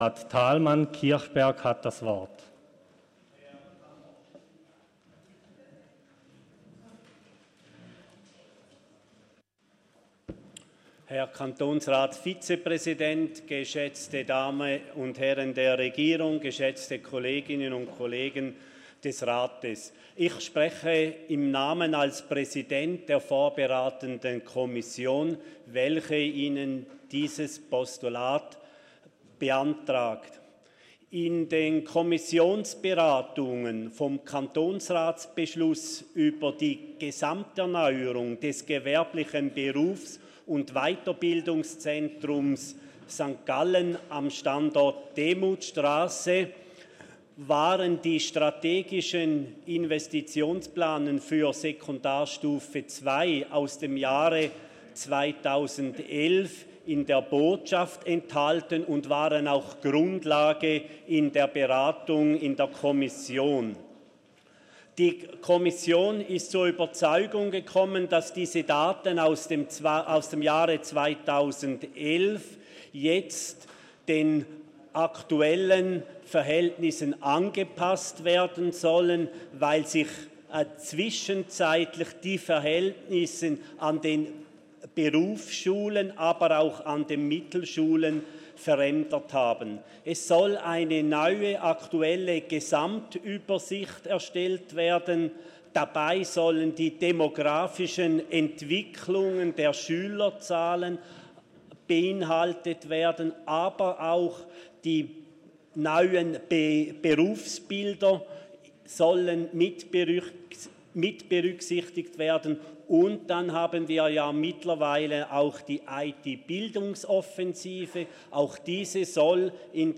Kommissionspräsident der vorberatenden Kommission 35.18.05, die das Postulat eingereicht hat:
Session des Kantonsrates vom 11. bis 13. Juni 2019